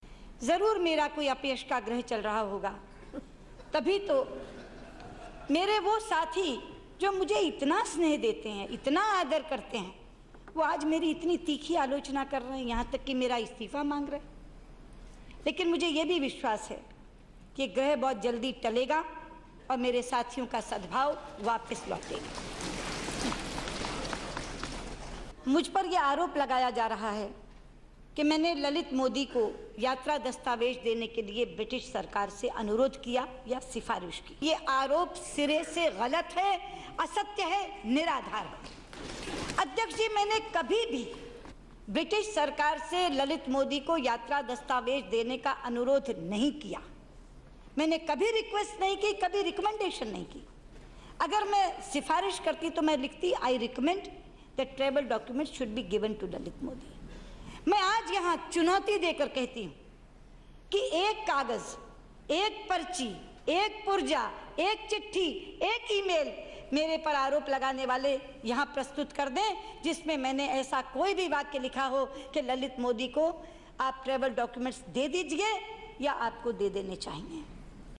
ललित मोदी के मुद्दे पर सुषमा का बयान